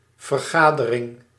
Ääntäminen
Tuntematon aksentti: IPA: /vərˈχadəˌrɪŋ/